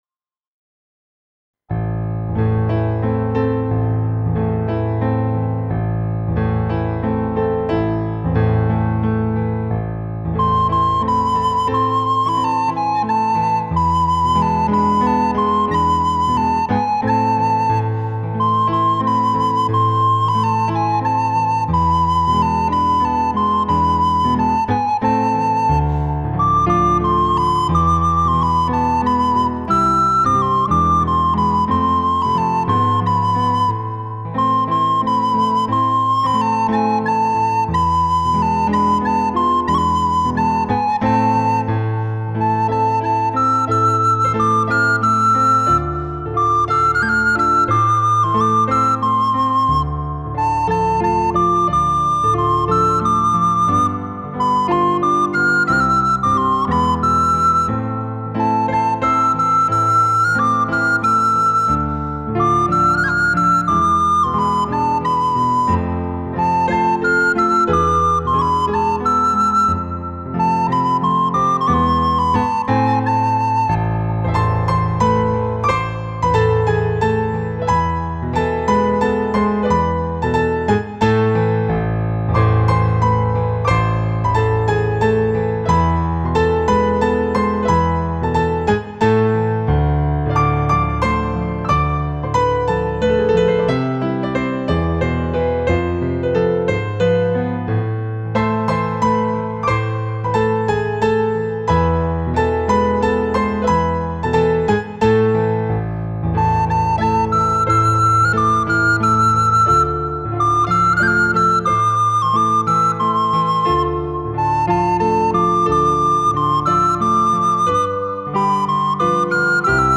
سرشار از لطافت، شادابی و حس زندگی است
• اجرای دقیق و وفادار به نت‌های تنظیم‌شده
ایرانی